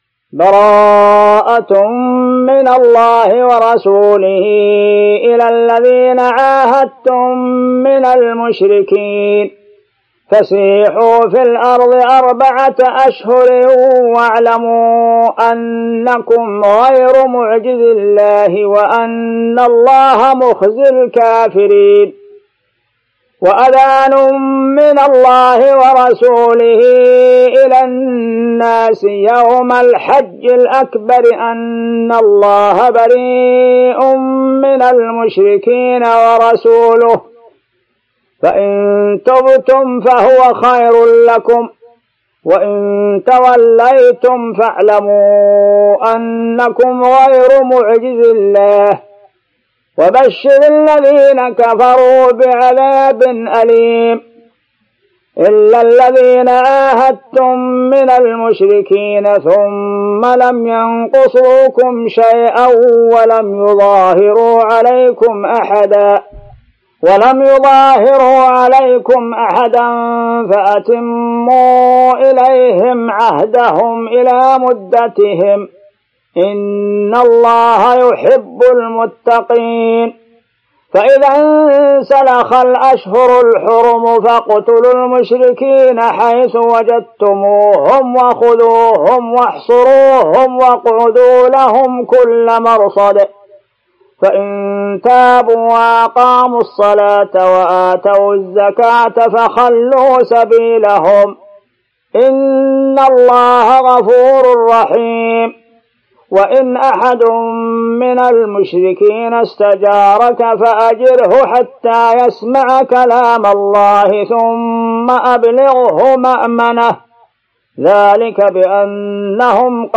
سورة التوبة | Surah At-Tawbah > المصحف المرتل